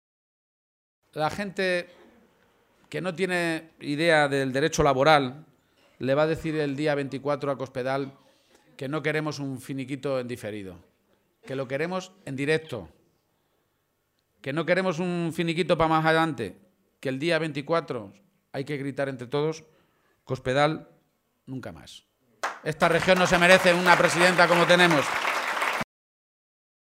Momento del acto público en Montiel